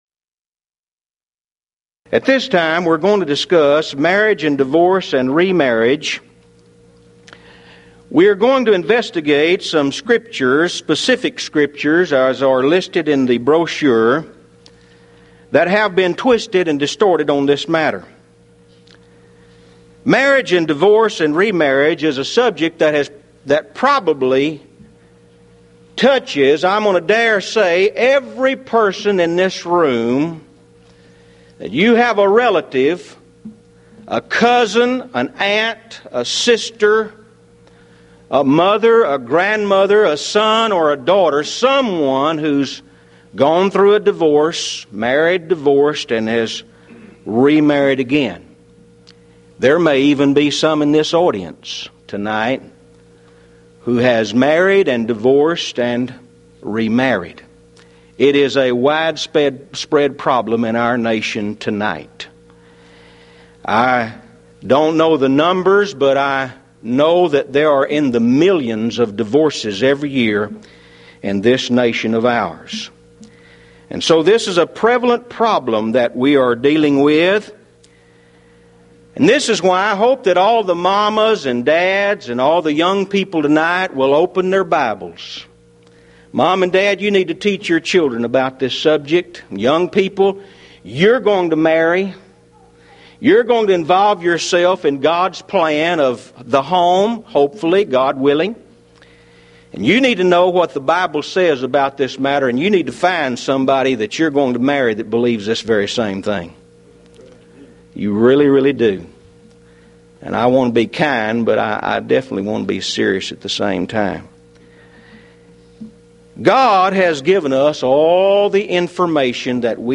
Mid-West Lectures Event: 1995 Mid-West Lectures